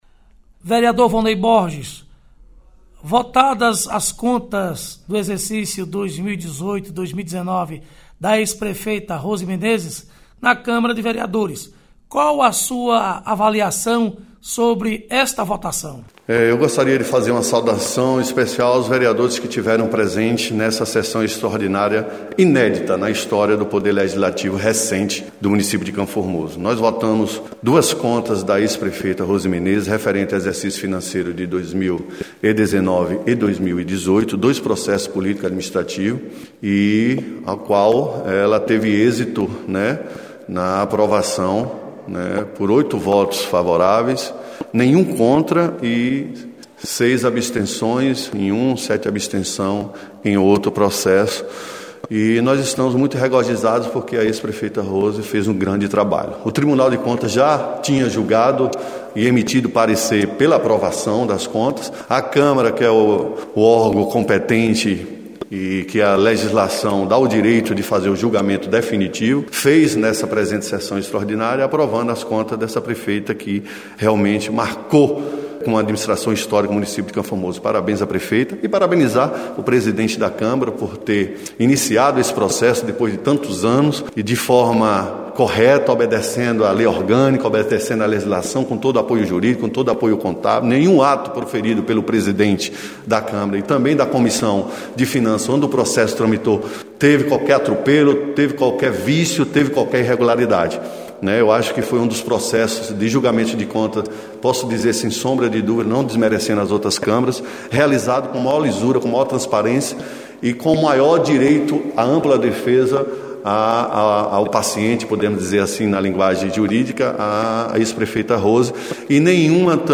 Entrevista: Vereadores – votação das contas da ex prefeita Rose Menezes